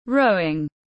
Môn chèo thuyền tiếng anh gọi là rowing, phiên âm tiếng anh đọc là /ˈrəʊ.ɪŋ/ .
Rowing /ˈrəʊ.ɪŋ/
Rowing.mp3